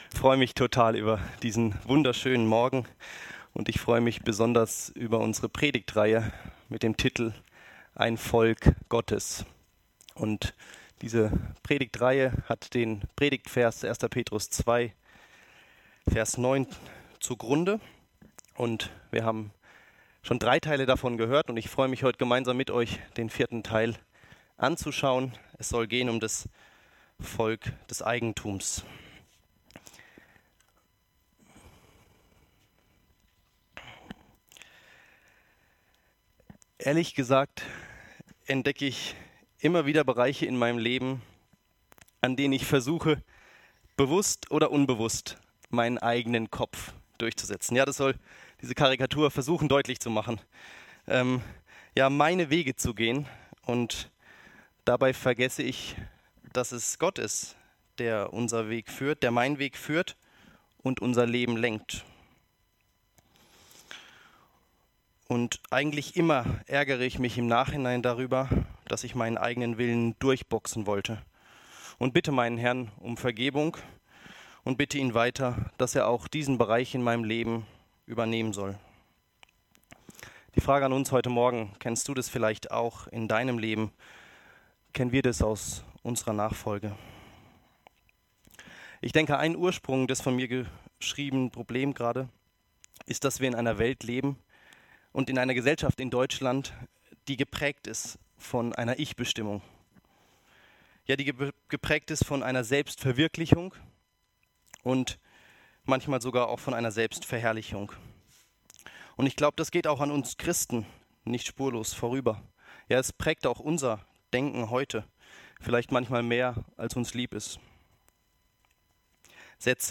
Aus der Predigtreihe: "Ein Volk Gottes"